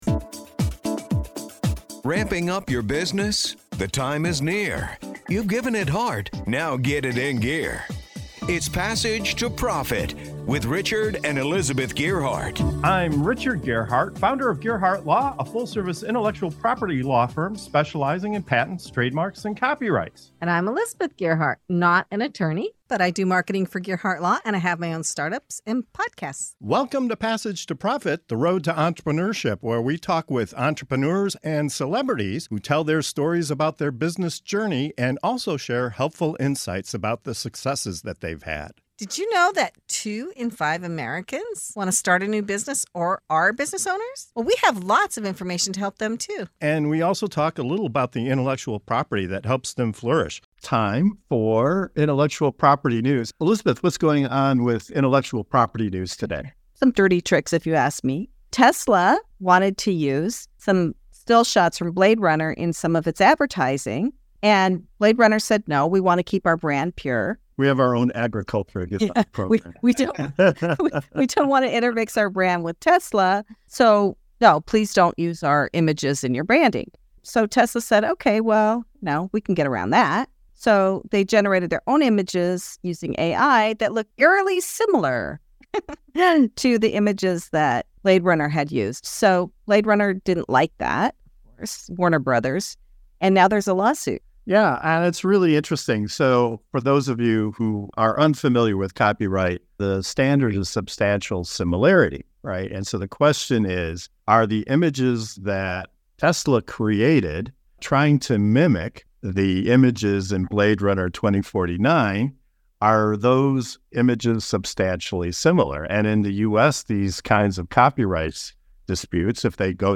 Our panel unpacks the "substantial similarity" test, the role of experts in copyright disputes, and what this means for creatives, brands, and the future of AI.